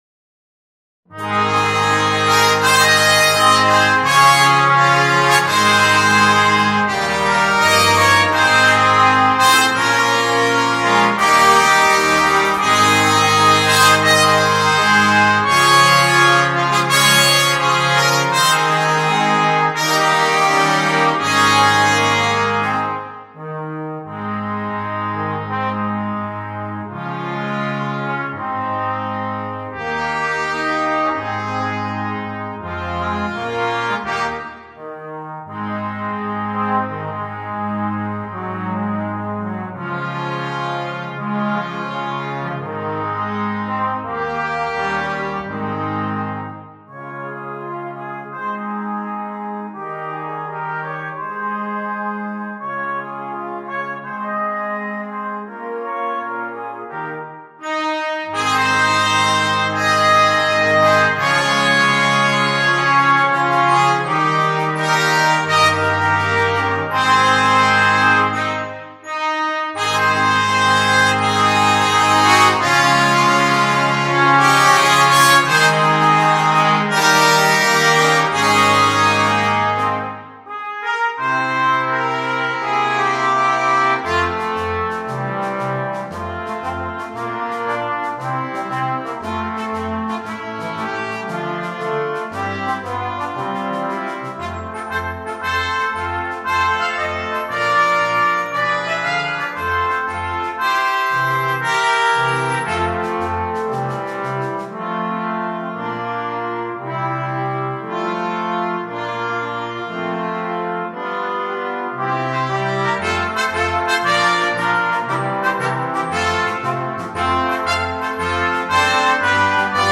2. молодежный духовой оркестр (гибкий)
8 Детали и перкуссия
без сольного инструмента
Легкая музыка
A Scottish Impression